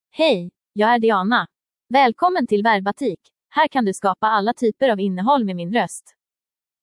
DianaFemale Swedish AI voice
Diana is a female AI voice for Swedish (Sweden).
Voice: DianaGender: FemaleLanguage: Swedish (Sweden)ID: diana-sv-se
Voice sample
Diana delivers clear pronunciation with authentic Sweden Swedish intonation, making your content sound professionally produced.